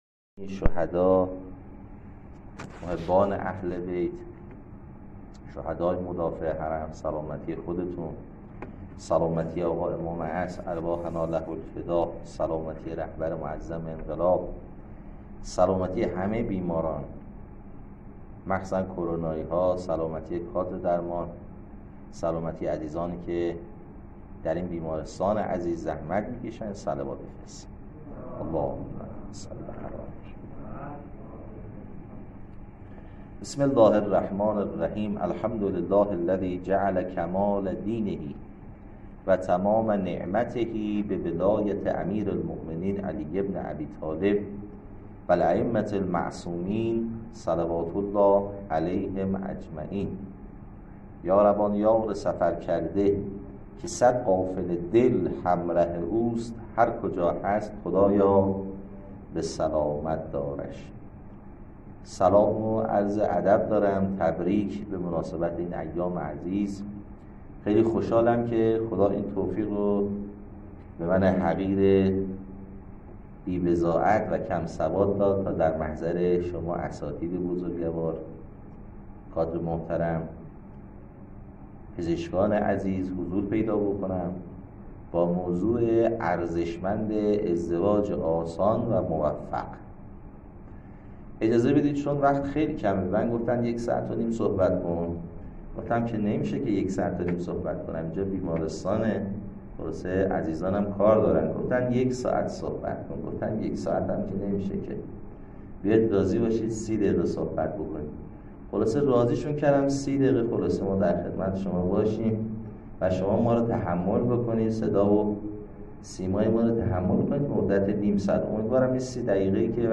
کارگاه اموزشی بیمارستان بعثت